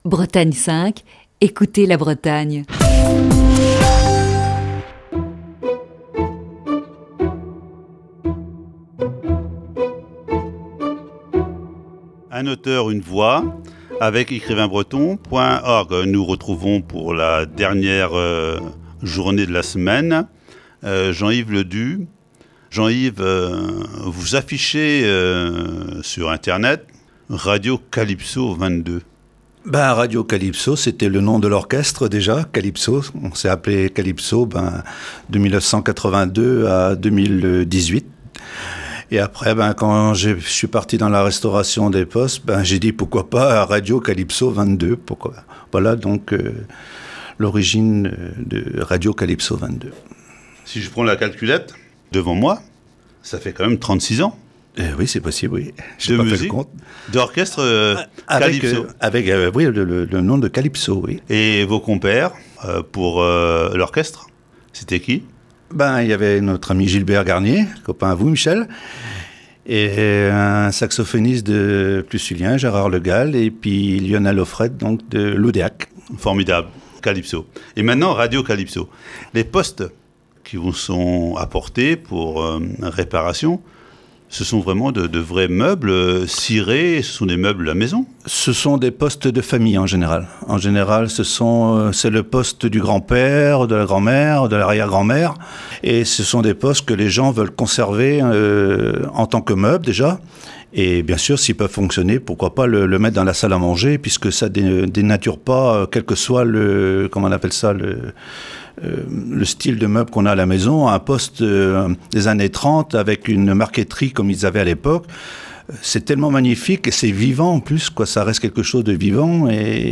Voici ce vendredi la dernière partie de cette série d'entretiens.